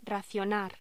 Locución: Racionar
voz
Sonidos: Voz humana